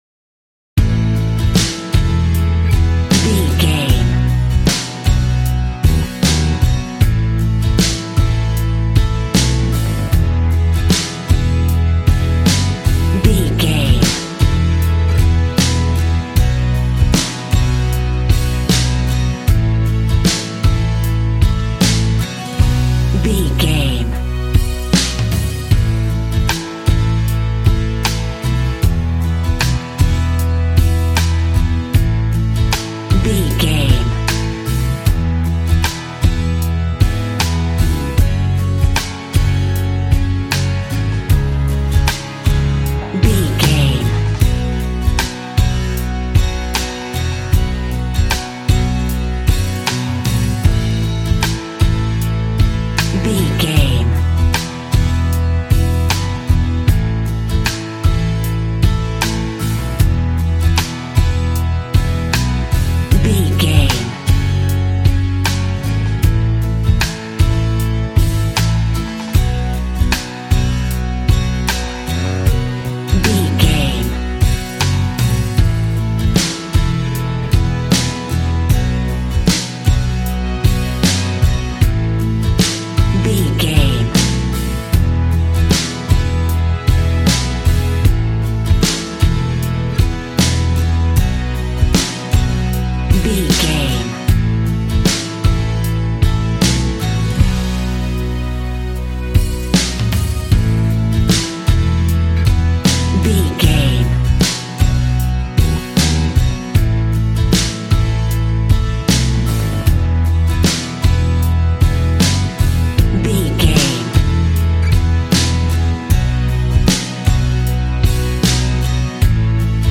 Ionian/Major
hard
groovy
powerful
electric guitar
bass guitar
drums
organ